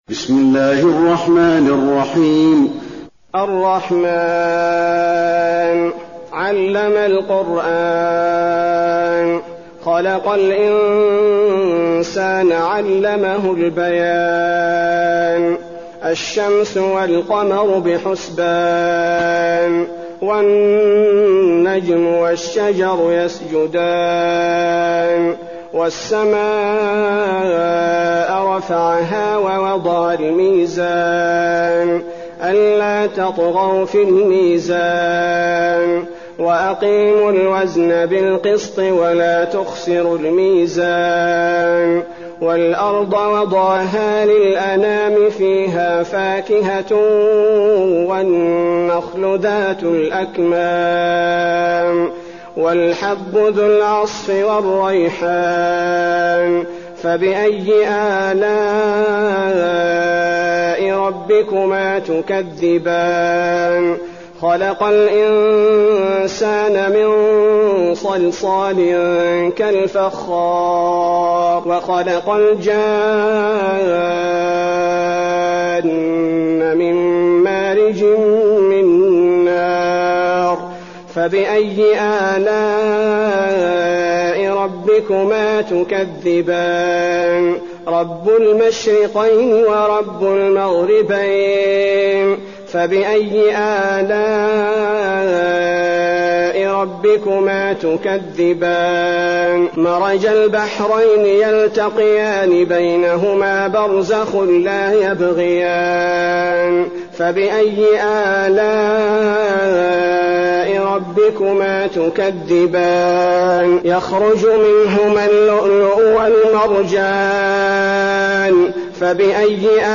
المكان: المسجد النبوي الرحمن